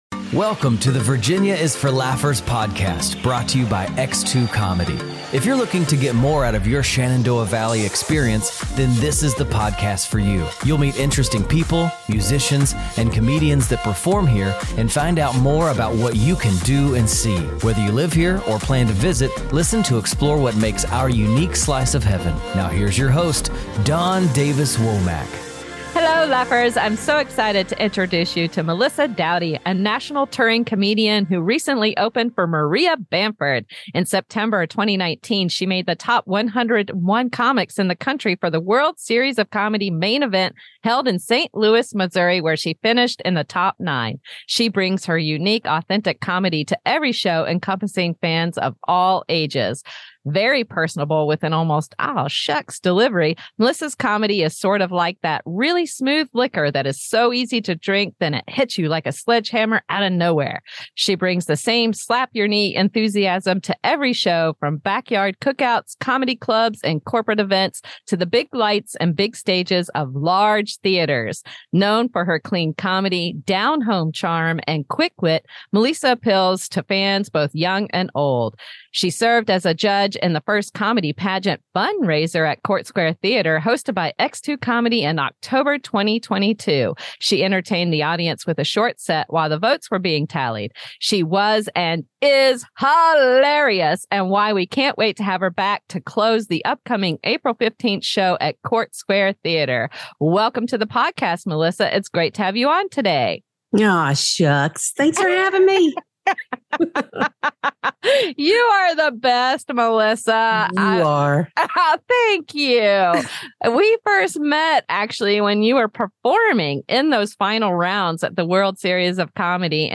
humorous and candid chat